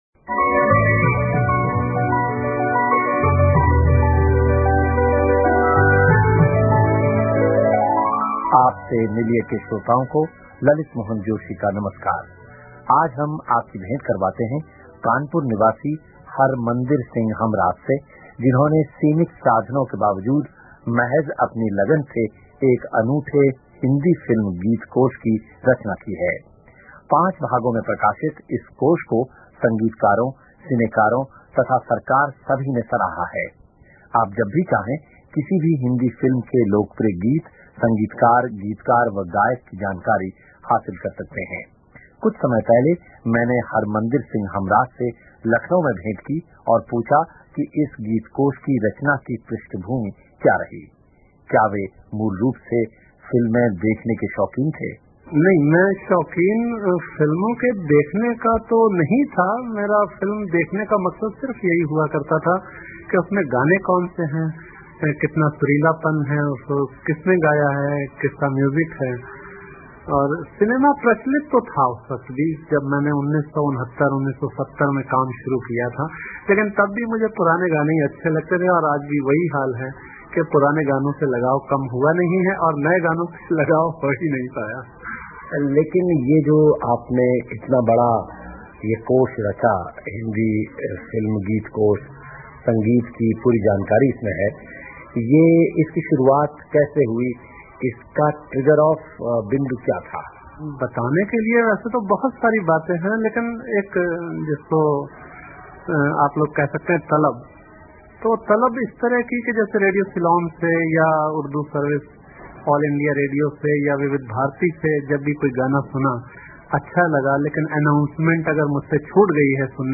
Here is the mp3 file of his interview with BBC and an English translation by me